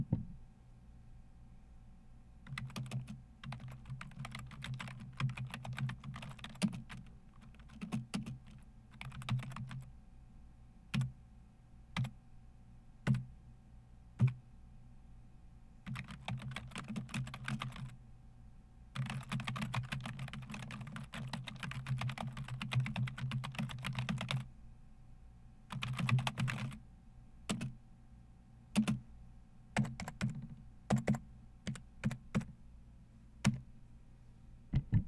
Typing_heavy keyboard
fast keyboard typing sound effect free sound royalty free Memes